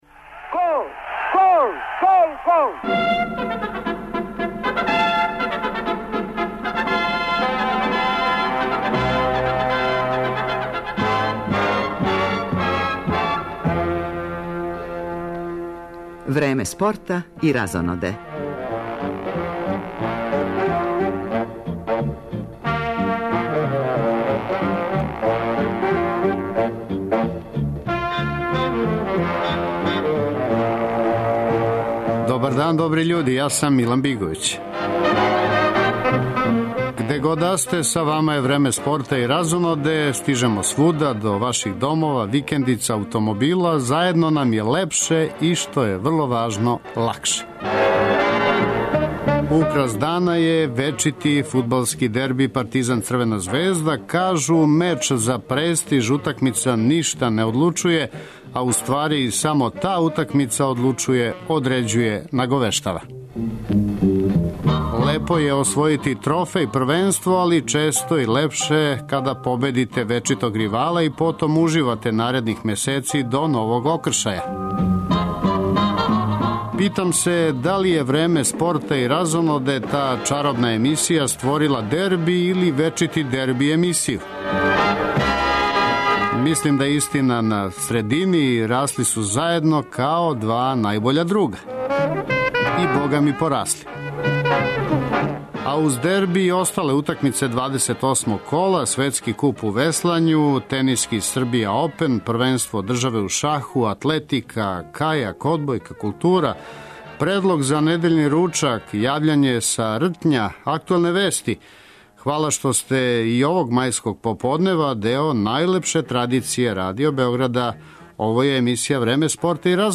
Радио Београд 1, 15.30